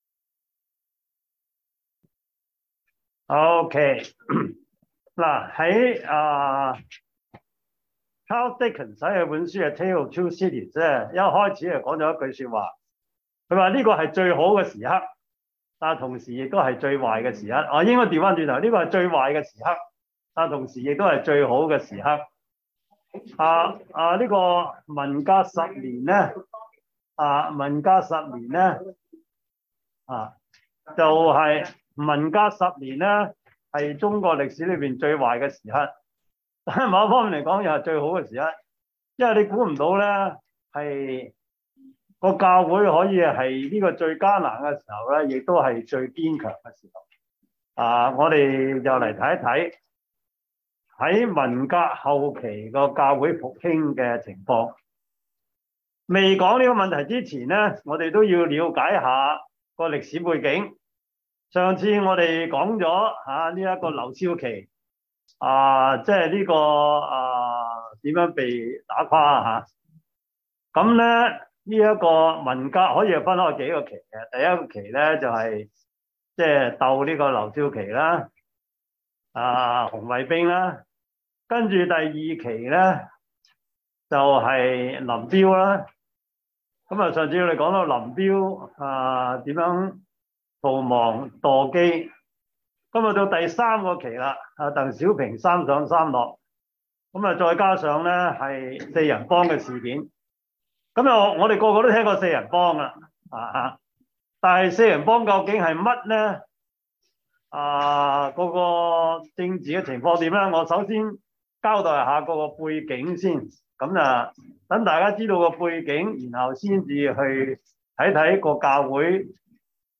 教會歷史 Service Type: 中文主日學 中國教會史